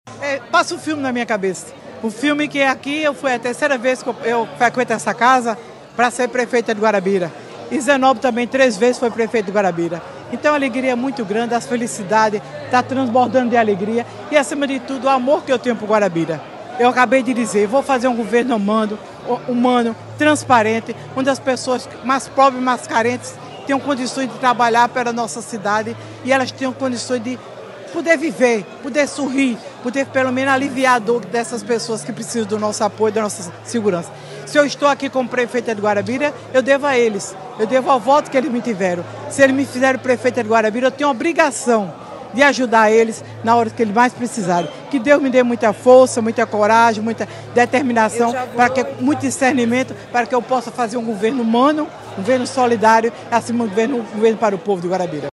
Os comentários de Léa Toscano foram registrados pelo programa Correio Debate, da 98 FM, de João Pessoa, nesta quinta-feira (02/01).